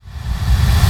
Reverse Kickverb 1.wav